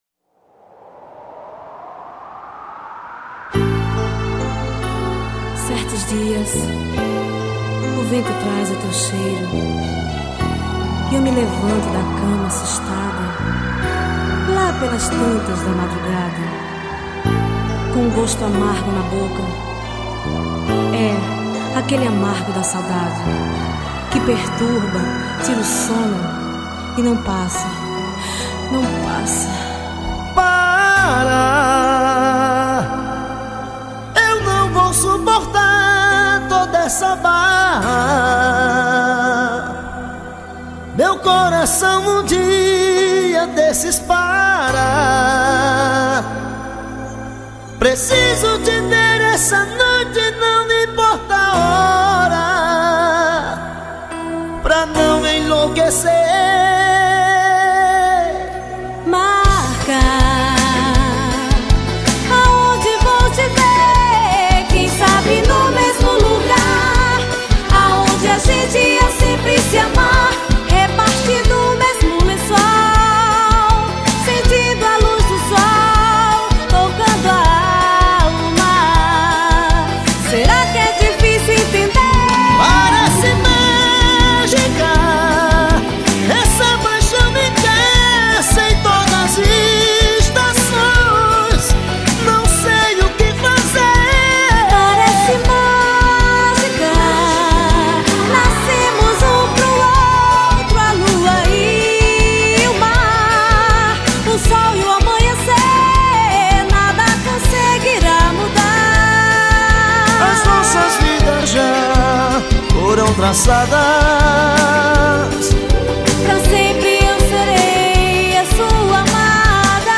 2024-12-19 08:55:24 Gênero: Forró Views